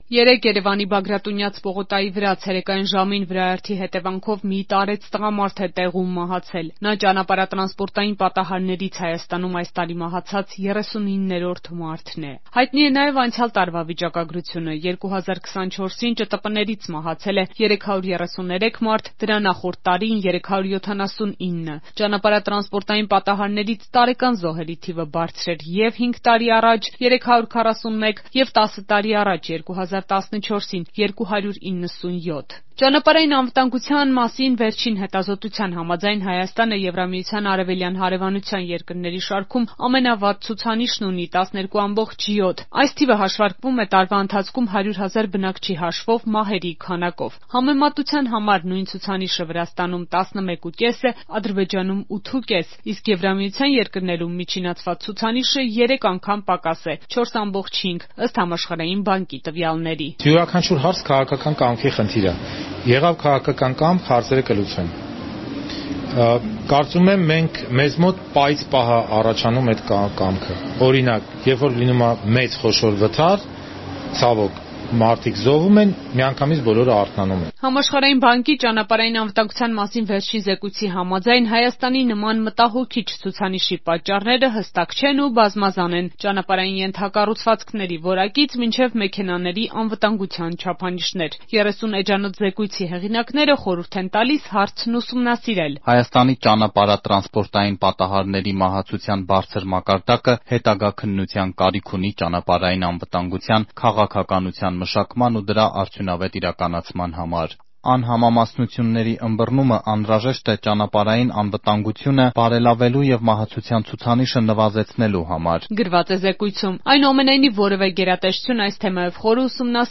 Ռեպորտաժներ